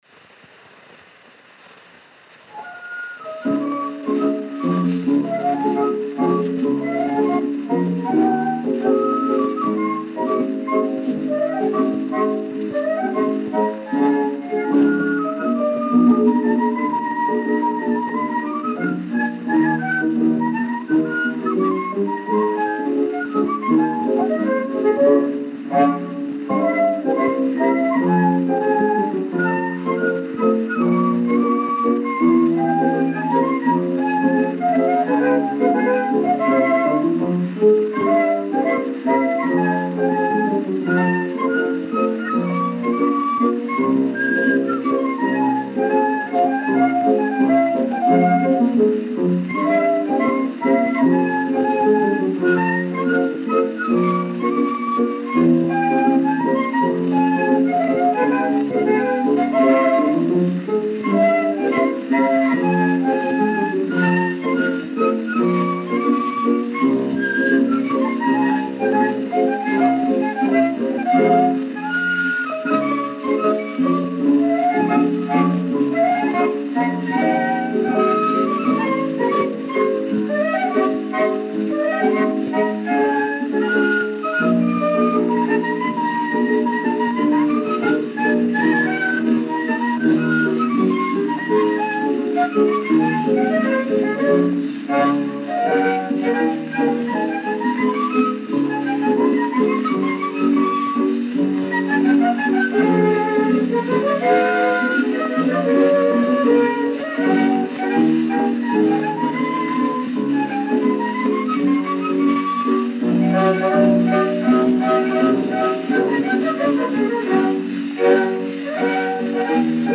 O gênero musical foi descrito como "Tango".